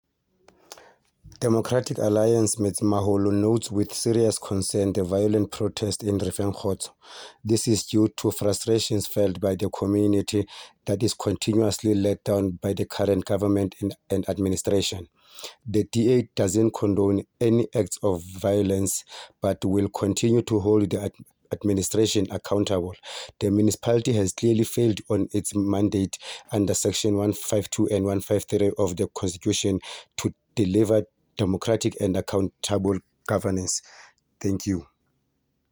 Sesotho soundbites by Cllr Thulani Mbana